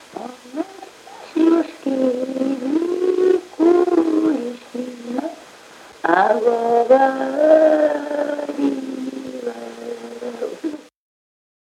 Оканье (полное оканье, свойственное Поморской группе севернорусского наречия – это различение гласных фонем /о/ и /а/ во всех безударных слогах)